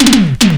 02_08_drumbreak.wav